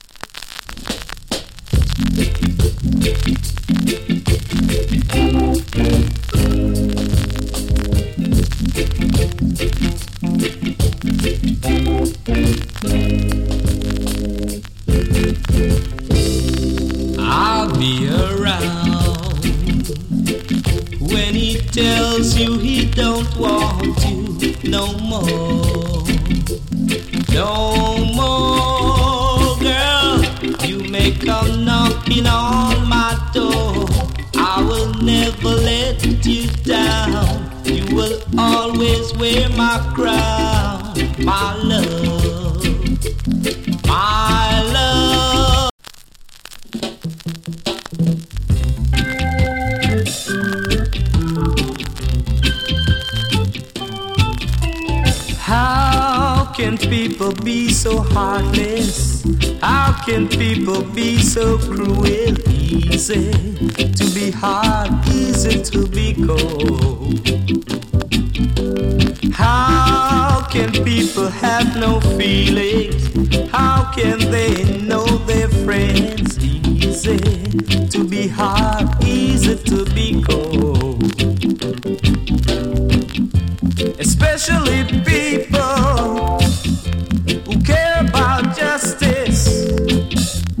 チリ、パチノイズ多数有り。
EARLY 70'S の NICE VOCAL EARLY REGGAE.